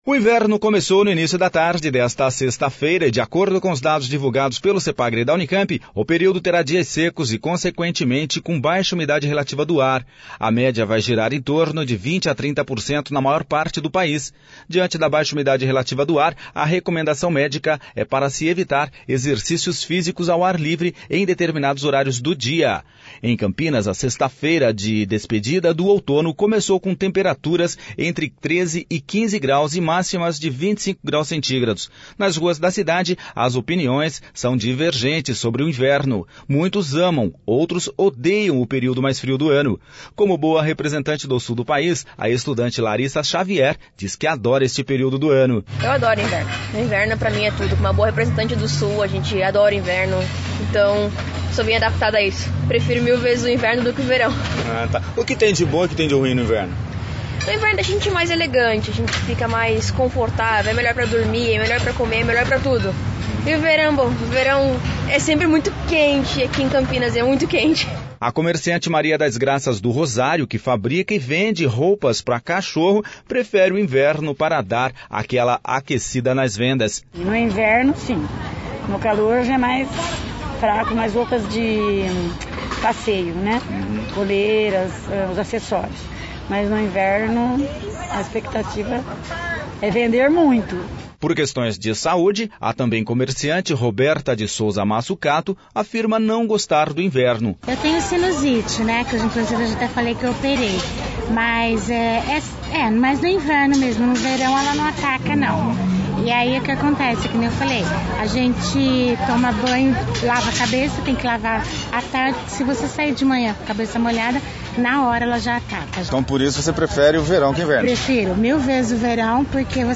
Nas ruas de Campinas as opiniões sobre  o inverno são divergentes.